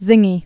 (zĭngē)